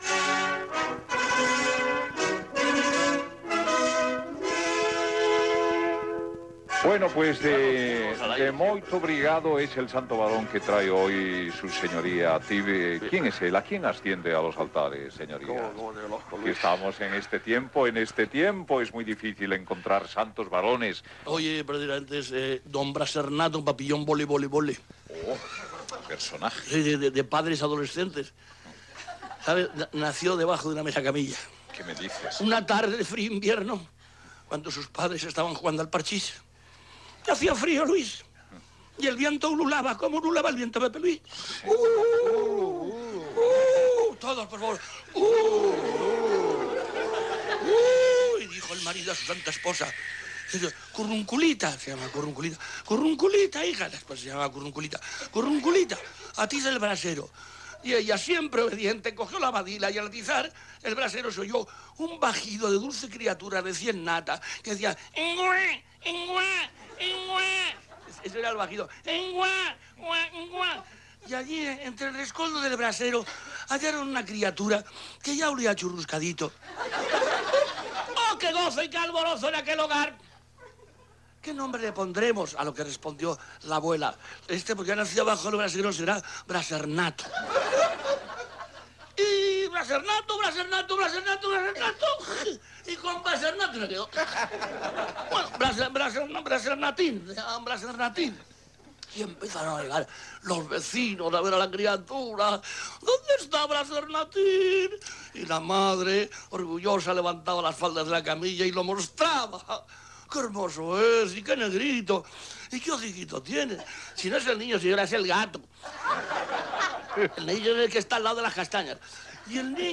Fragment de la secció humorística "El estado de la nación" amb l'espai "Los santos barones" amb Luis Sánchez Polack "Tip"
Entreteniment